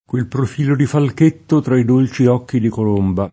kUel prof&lo di falk%tto tra i d1l©i 0kki di kol1mba] (Ojetti) — sim. i cogn. Falchetti, Falchetto